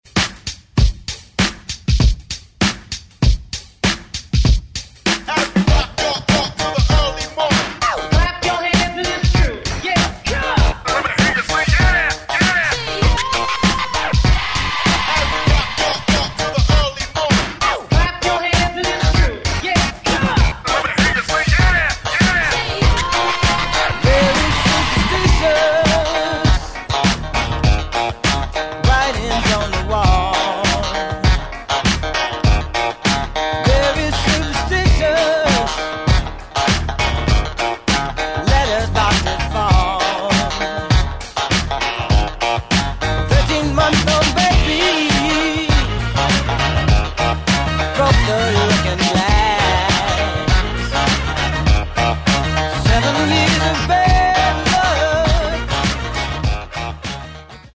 Genres: 2000's , TOP40 Version: Clean BPM: 121 Time